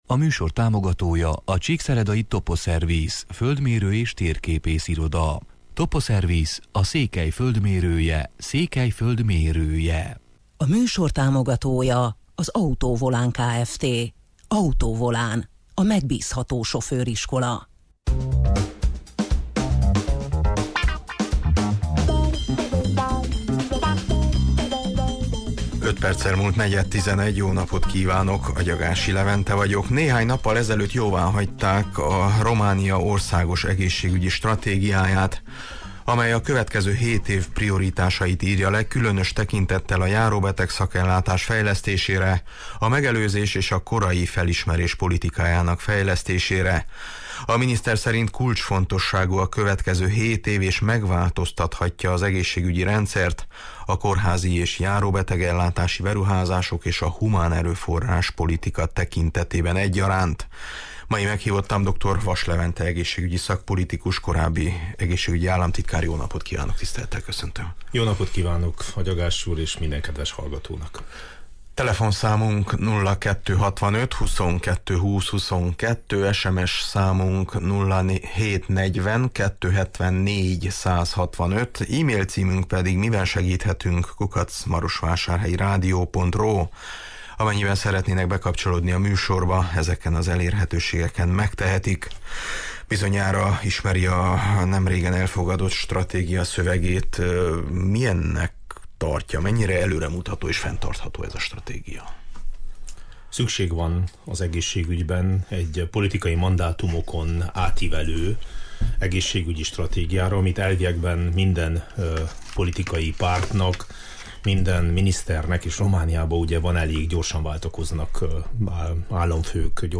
Mai meghívottam Dr. Vass Levente egészségügyi szakpolitikus: